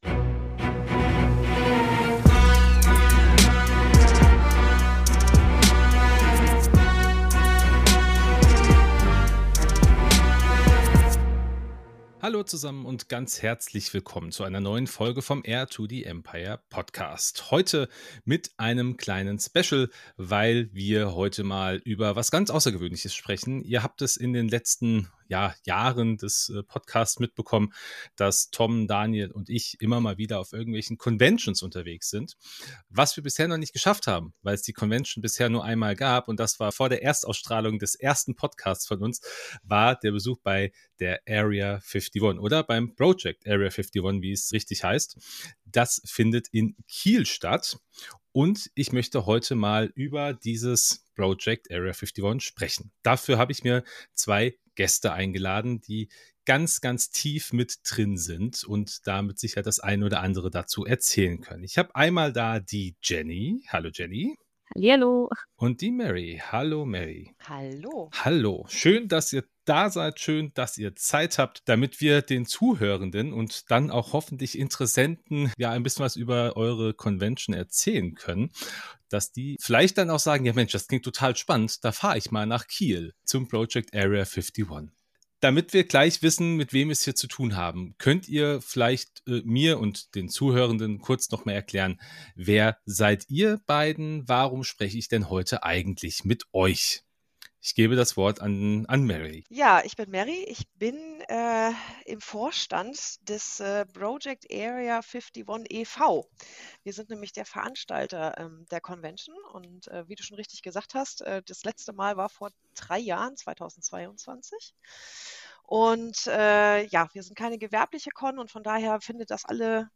Cos-Talk – das Cosplay-Interview-Format von Heir to the Empire nimmt euch mit in die faszinierende Welt der Star Wars-Cosplayer.